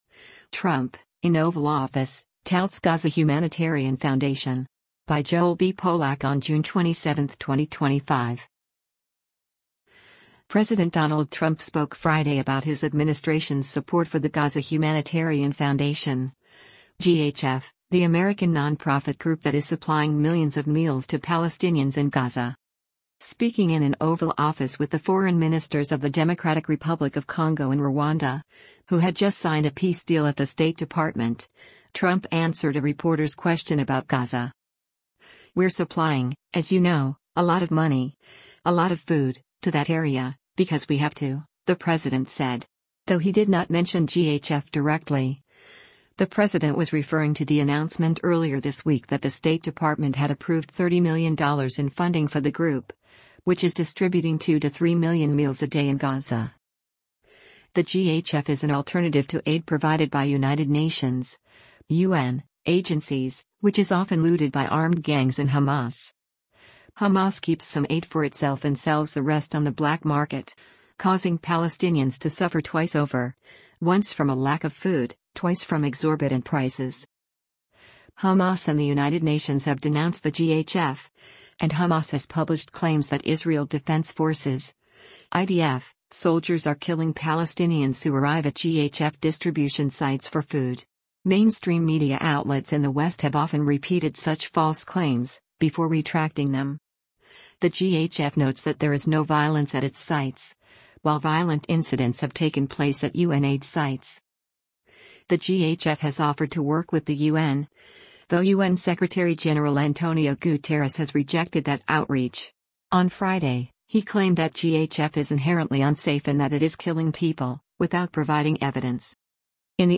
Speaking in an Oval Office with the foreign ministers of the Democratic Republic of Congo and Rwanda, who had just signed a peace deal at the State Department, Trump answered a reporter’s question about Gaza.